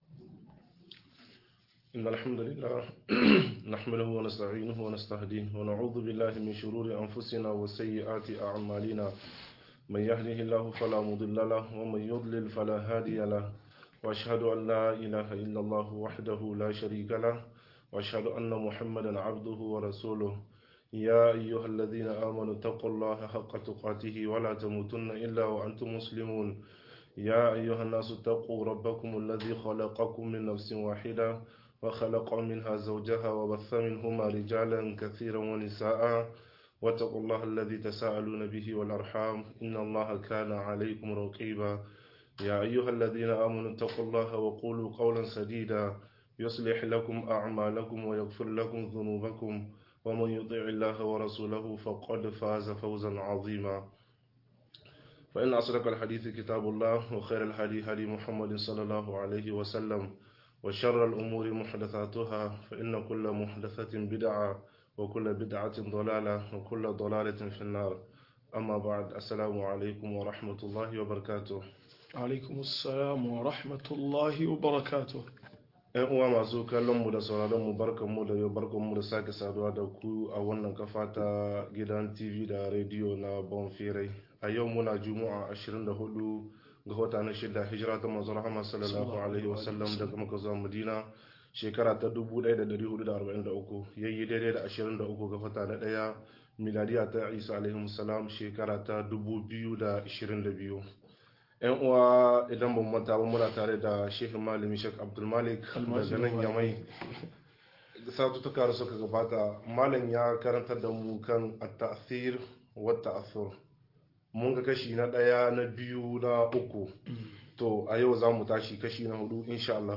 Yin tasiri da tasirantuwa-04 - MUHADARA